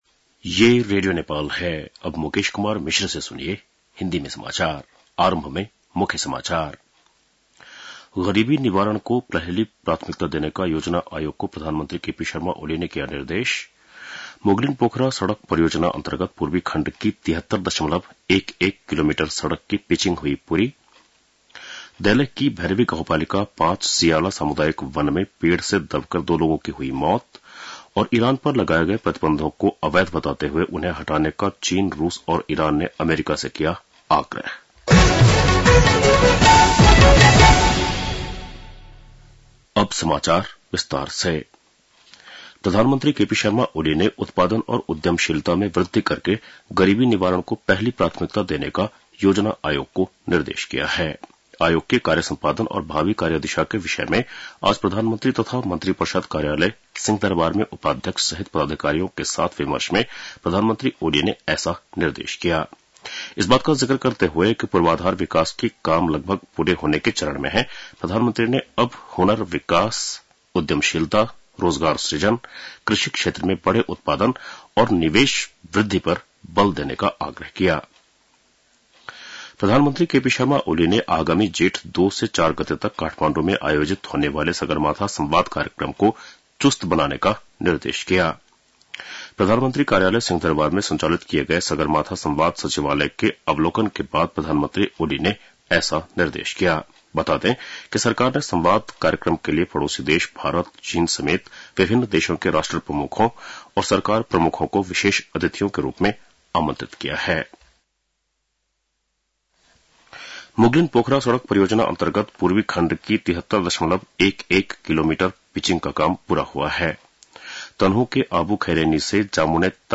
बेलुकी १० बजेको हिन्दी समाचार : १ चैत , २०८१